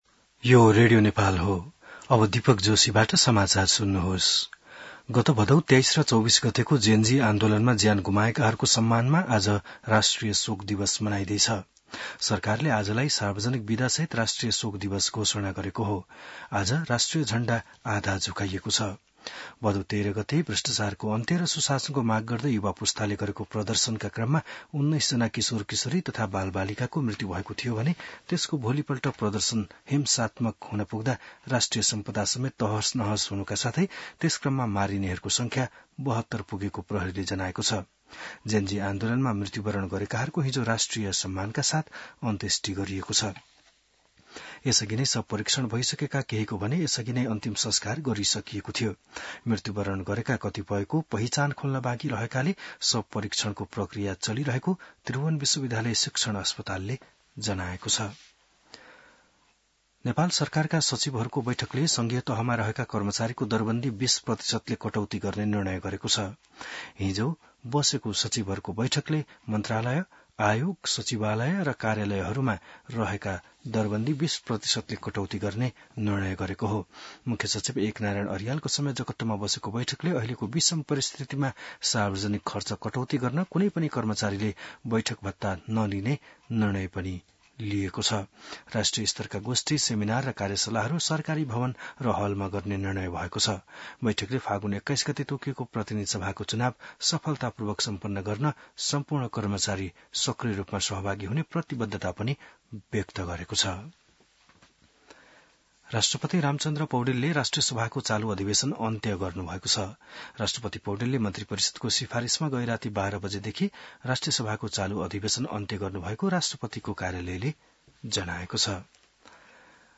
बिहान १० बजेको नेपाली समाचार : १ असोज , २०८२